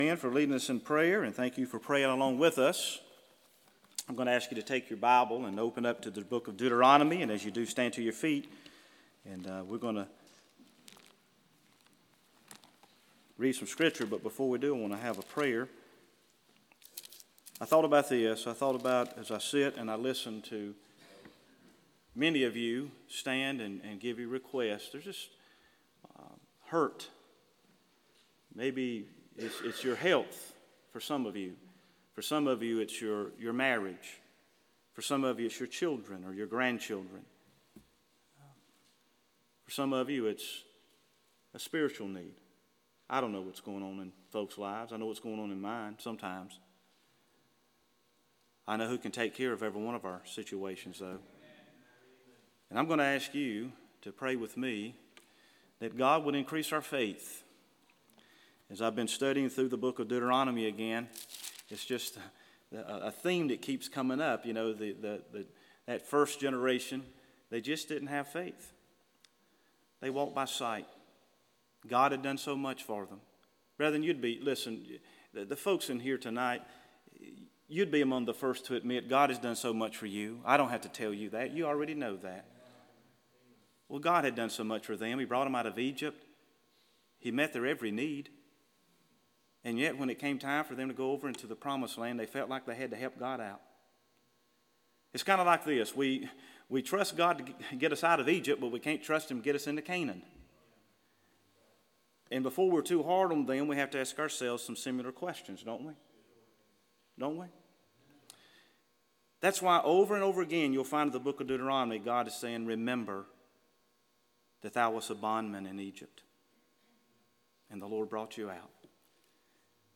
Sermons Archive • Page 53 of 166 • Fellowship Baptist Church - Madison, Virginia